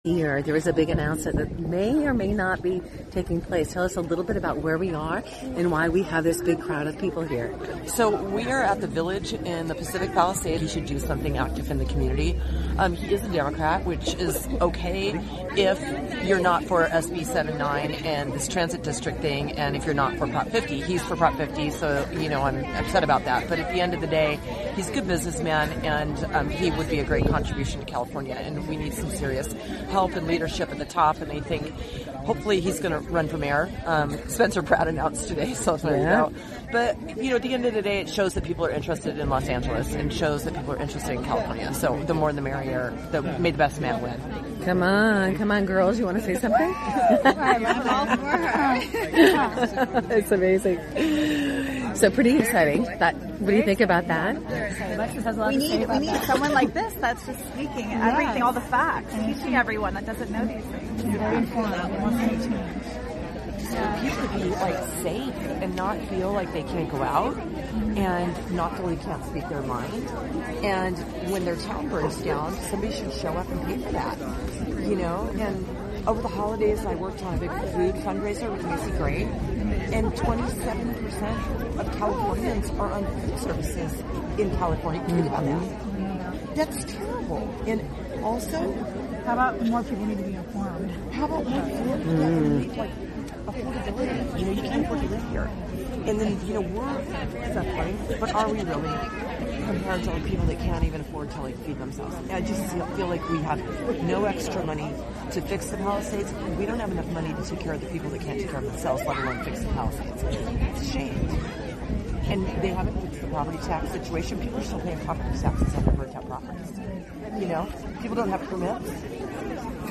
A big gathering in Pacific Palisade village brought hope and unity. A speaker praised a businessman who might run for mayor, aiming for better leadership in California. Issues like food insecurity and high living costs were discussed. The event ended with a special lighting ceremony, symbolizing hope and gratitude after the Eden Fire.